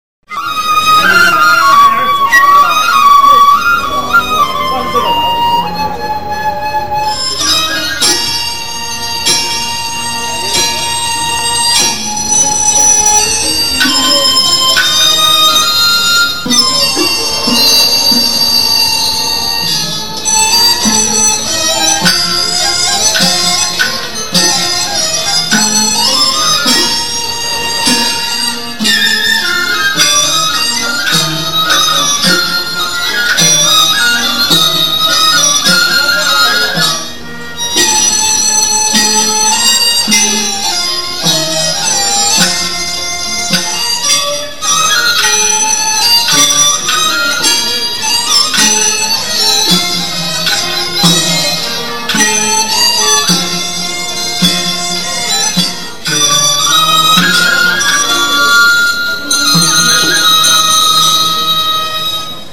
Nous parvenons sur une esplanade qui s'étend devant une sorte d'estrade recouverte où se produit un orchestre naxi. La musique qu'il interprète remonte à plusieurs siècles.
Parfois, un groupe de jeunes gens, hommes et femmes, viennent mimer sur l'estrade, aux sons d'instruments musicaux typiques (flûtes, cithares, percussions...), des scènes du folklore naxi, dont mon entendement occidental éprouve le plus grand mal à percevoir la signification.